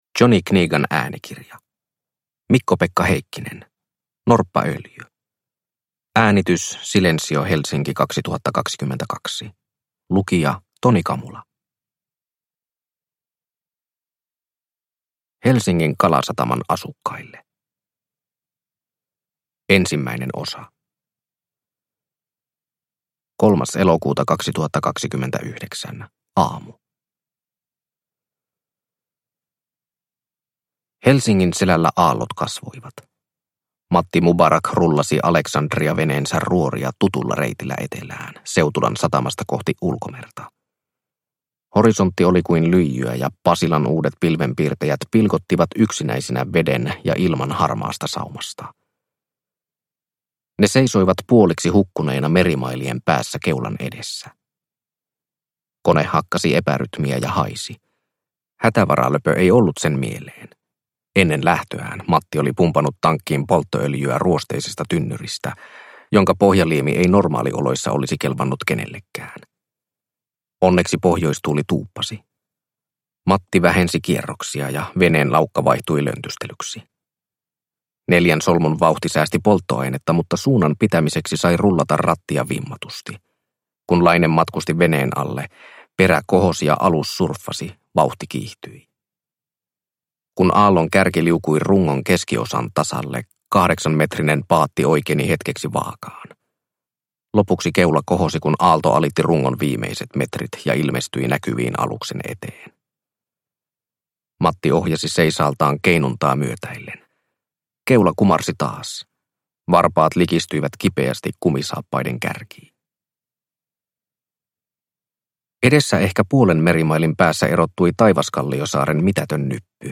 Norppaöljy – Ljudbok – Laddas ner